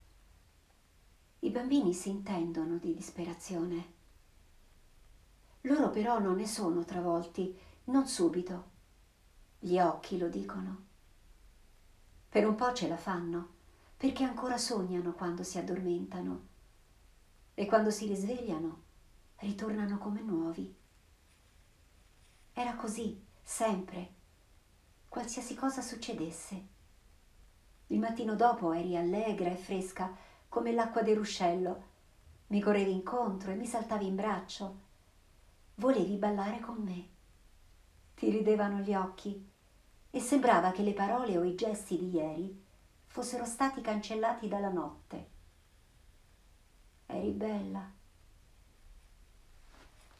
Per le lettrici e i lettori di Sabina Magazine ho scelto – anche con la mia lettura in audio – un brevissimo passo dal sapore poetico, che dà luce alla speranza e al futuro.